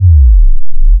sub_drop.wav